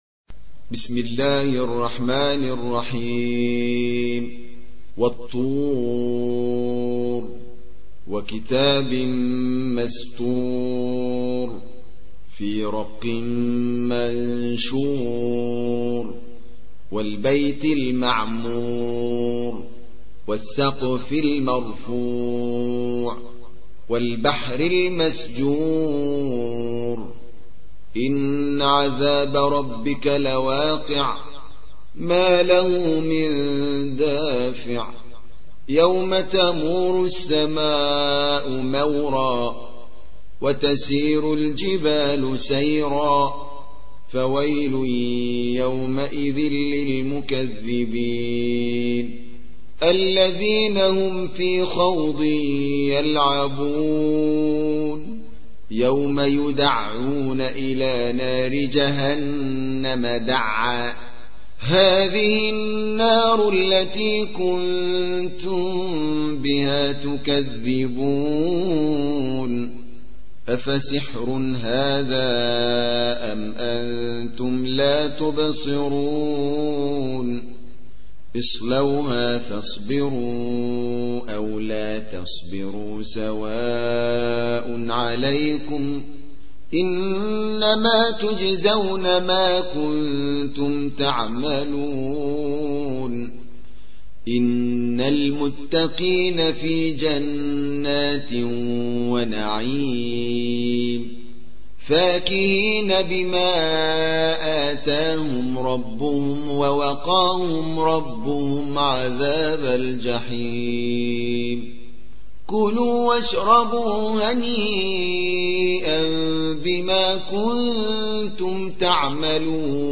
52. سورة الطور / القارئ